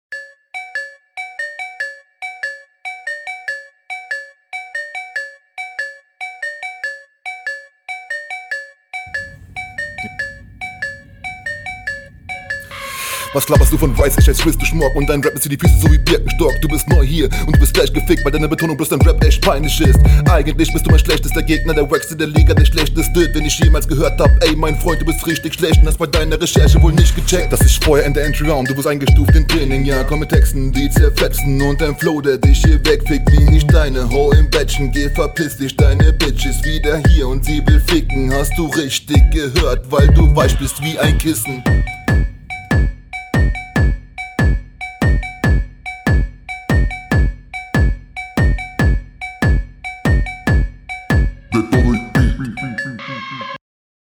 Hier mal was anderes du ... Nice. Beat liegt dir Doubletime auch nice. Quali ist …
du rapst hier schneller finde ich gut, technik ist auch ausgereifter als beim gegener aber …
Soundqualität: Es waren doubles dabei und hat sich clean angehört Flow: man hat ein paar …